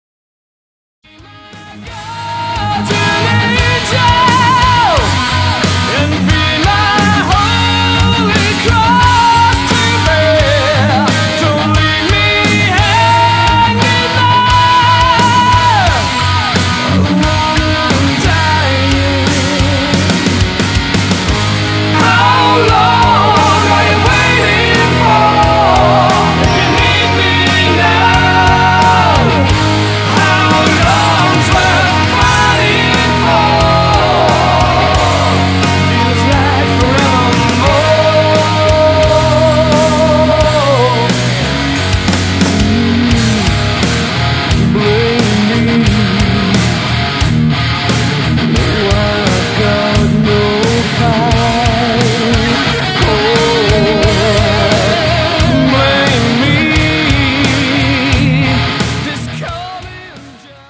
Vocals, Rhythm Guitar
Drums
Bass